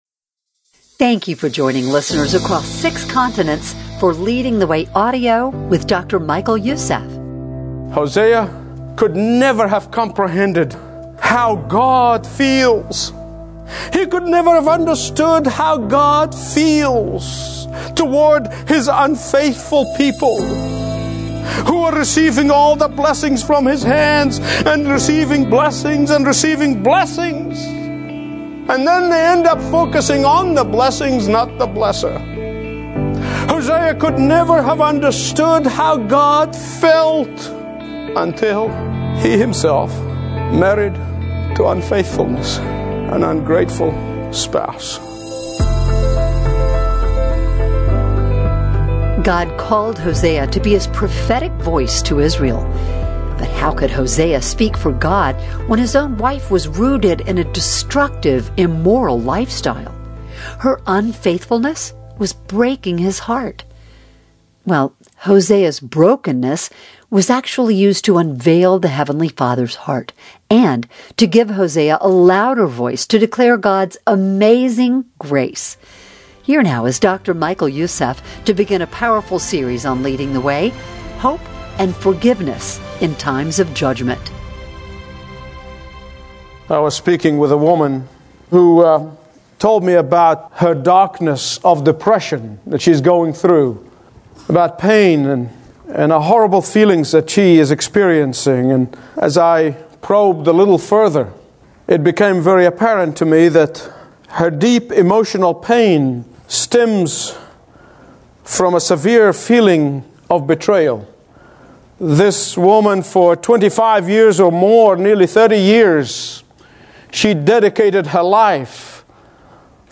Listen to Dr. Michael Youssef's Daily Teaching on The Blessing of Brokenness in HD Audio.